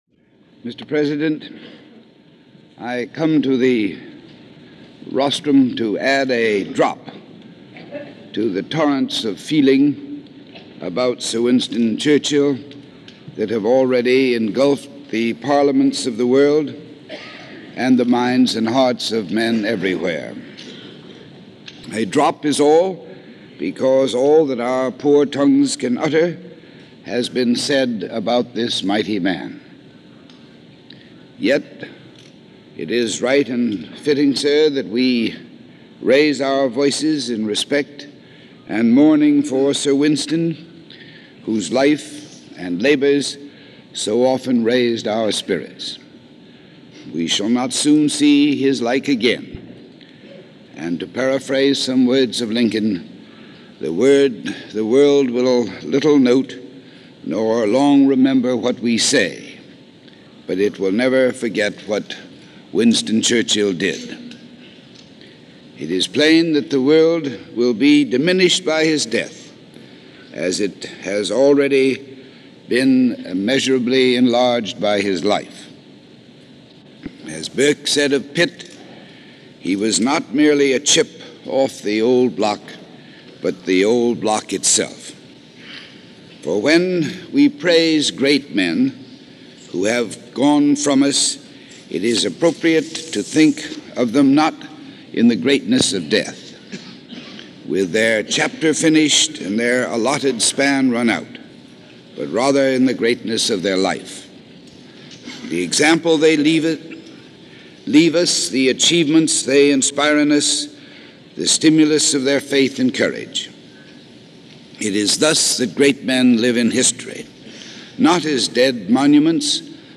Eulogy
I’ve included that complete address here (a little under an hour), as well as a Eulogy given on January 24, 1965 at the United Nations by UN Ambassador Adlai Stevenson.